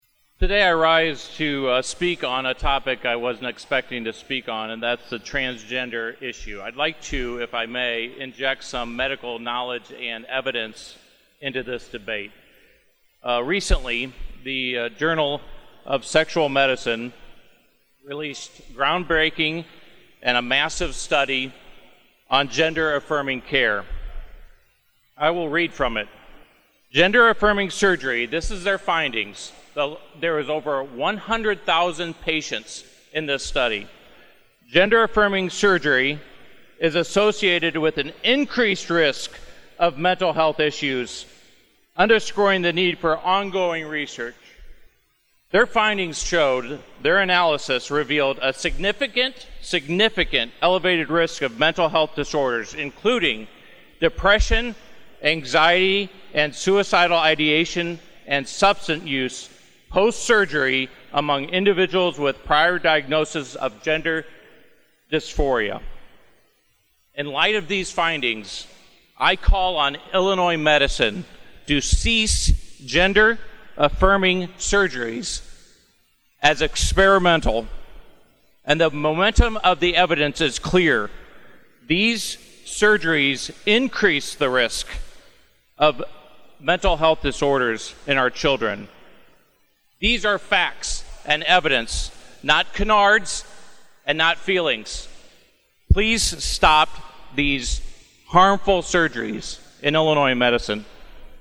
State Representative Bill Hauter, a physician from Morton, rose on the House floor Wednesday to speak on the subject of gender dysphoria.